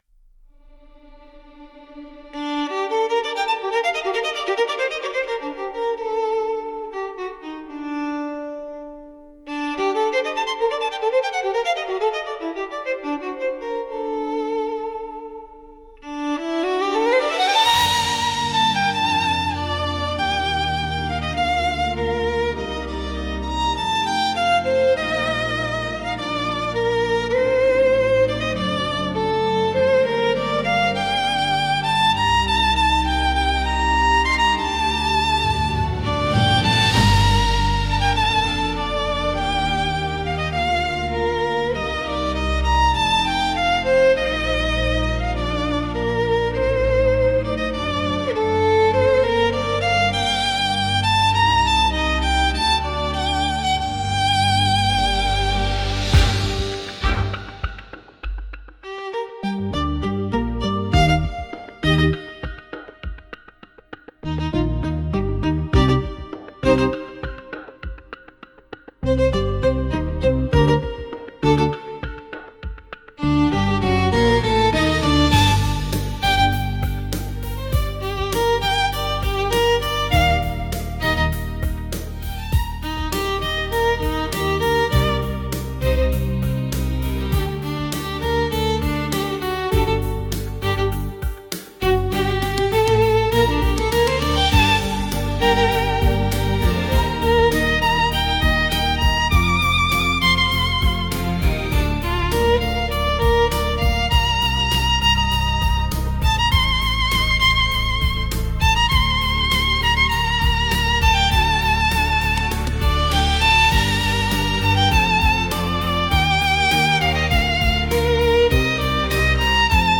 Instrumental / 歌なし
超絶技巧のソロ・バイオリンをフィーチャーした、華麗なクラシカル・クロスオーバー。